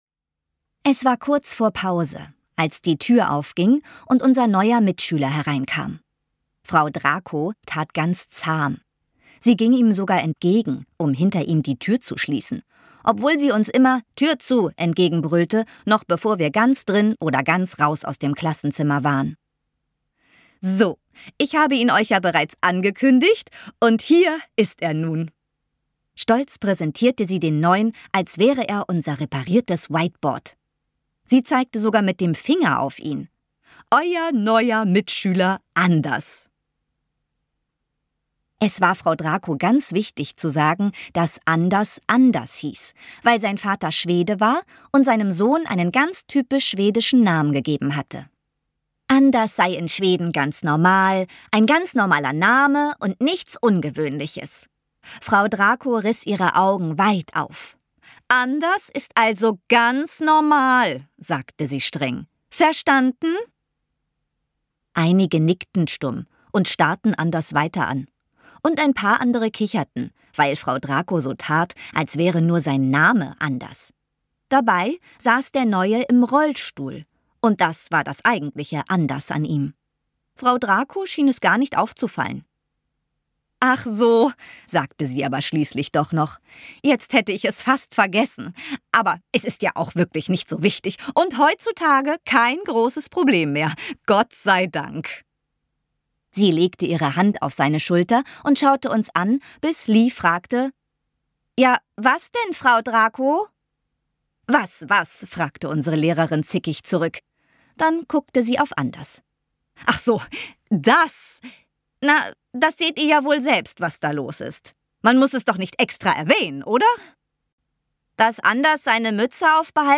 Mehr Enthusiasmus kann man wohl kaum in eine Geschichte mit einbringen.